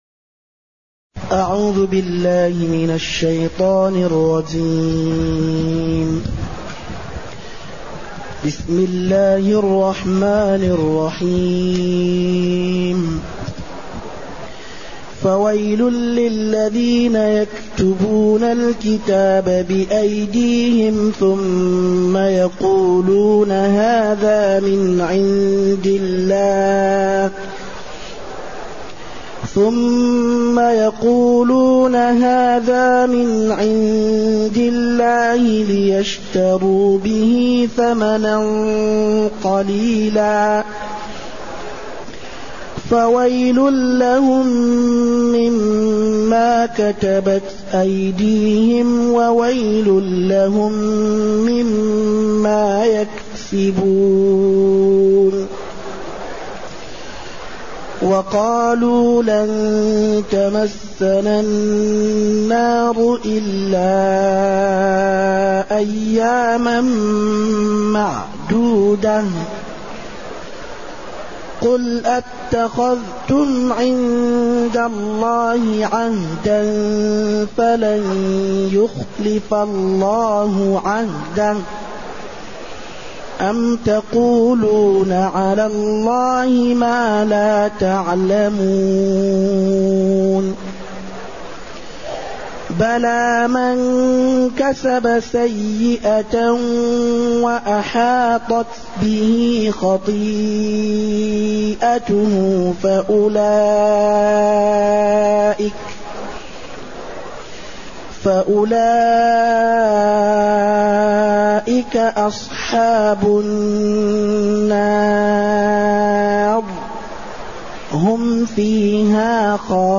تاريخ النشر ١٥ محرم ١٤٢٨ هـ المكان: المسجد النبوي الشيخ